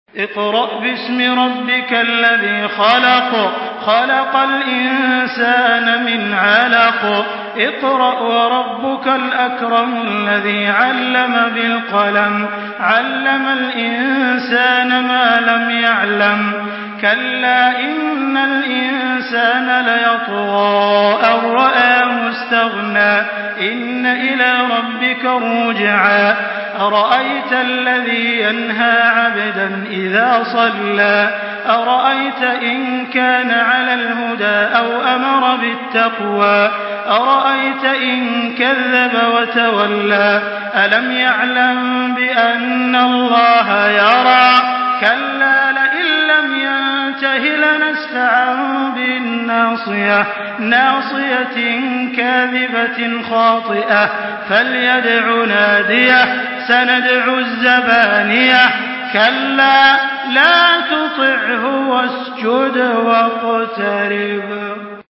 Surah আল-‘আলাক্ব MP3 by Makkah Taraweeh 1424 in Hafs An Asim narration.
Murattal